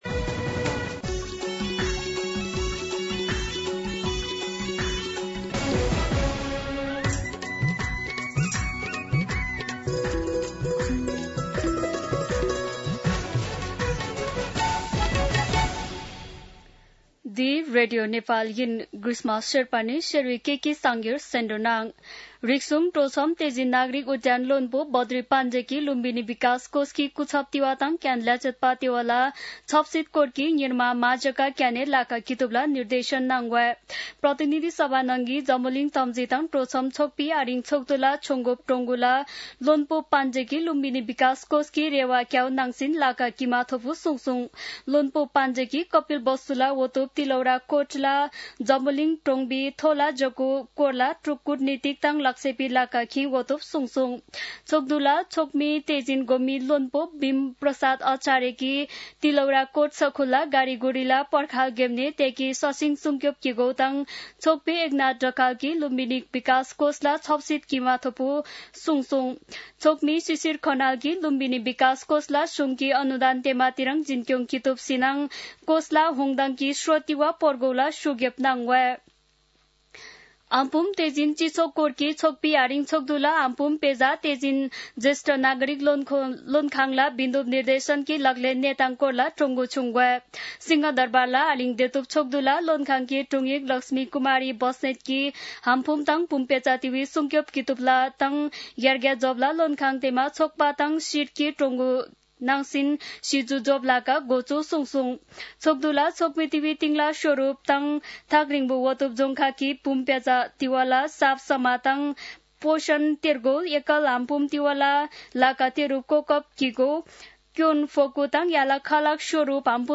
शेर्पा भाषाको समाचार : २२ साउन , २०८२